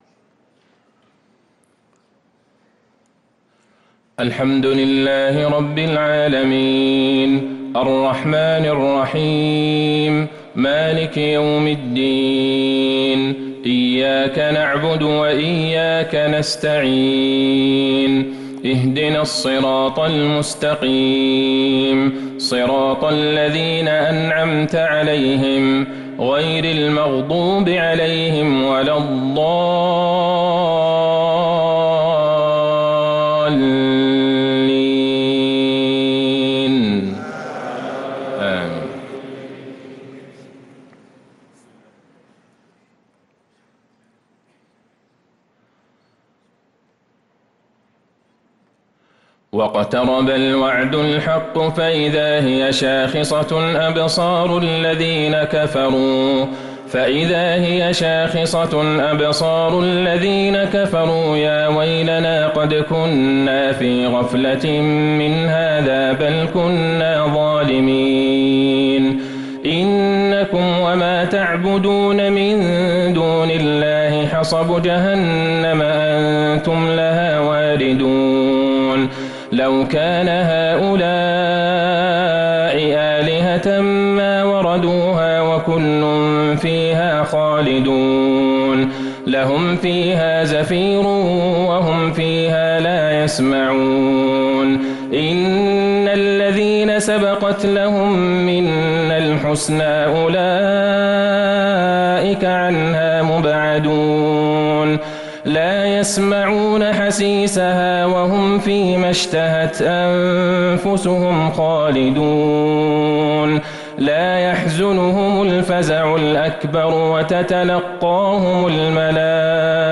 صلاة العشاء للقارئ عبدالله البعيجان 26 محرم 1445 هـ